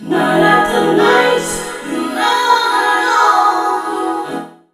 NIGHTVOCOD-L.wav